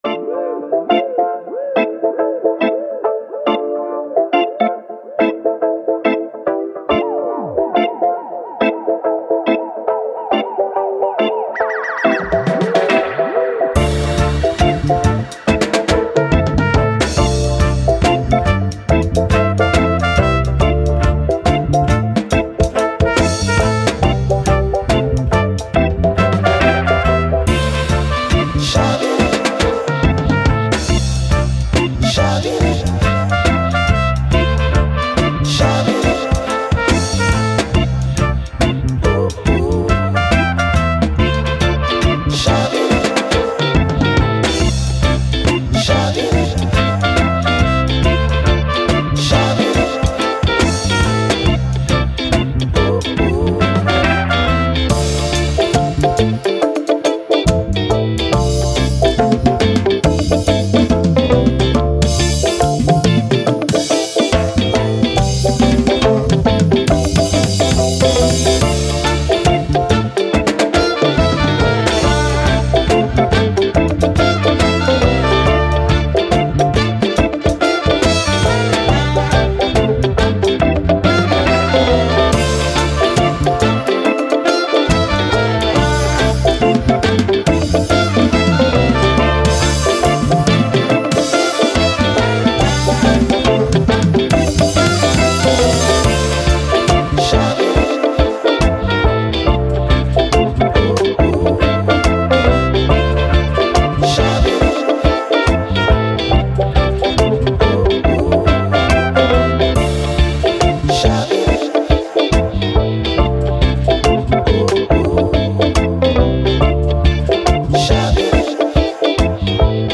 Reggae, Dub, Vibe, Sun, Beach